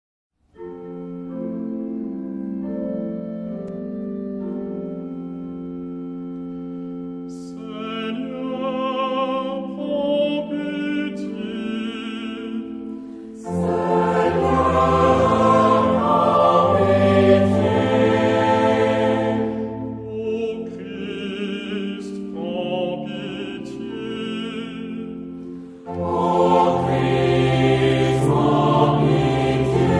Genre-Stil-Form: Litanei ; geistlich
Chorgattung: SATB  (4 gemischter Chor Stimmen )
Instrumente: Orgel (1)
Tonart(en): F-Dur